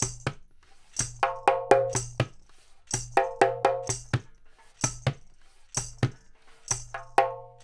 126-PERC1.wav